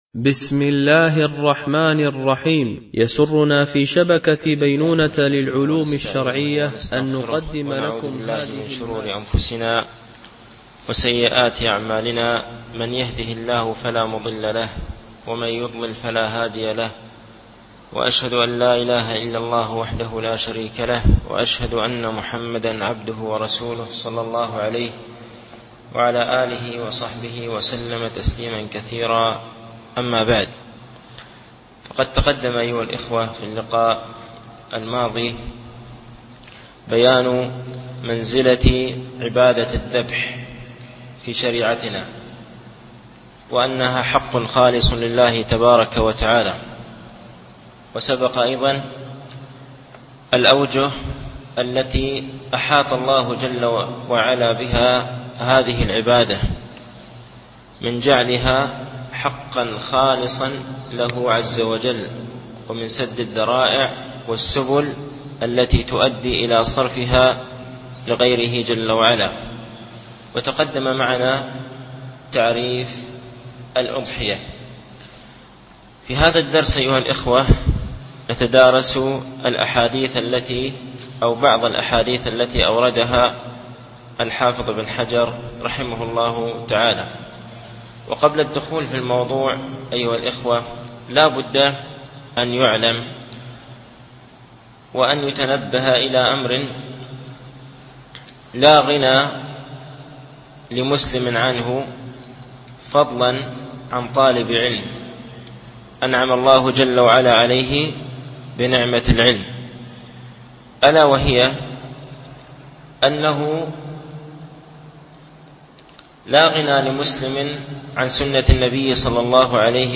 شرح كتاب الأضاحي من بلوغ المرام ـ الدرس الثاني